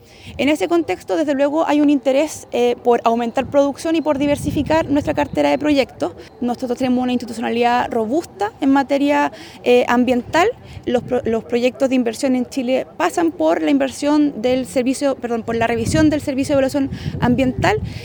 Consultada sobre esto, la subsecretaria de Minería, Suina Chahuán, indicó si bien hay interés en aumentar la producción y diversificar la cartera de proyectos, todas las iniciativas de inversión deben pasar por la evaluación ambiental.
subsecretaria-de-mineriua.mp3